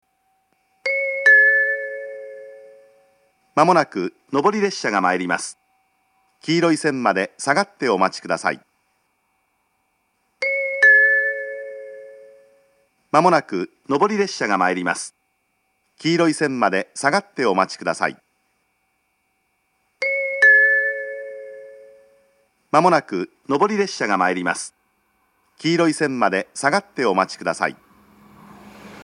狭いホームを新幹線が高速で通過することもあり、接近放送が導入されています。
上り接近放送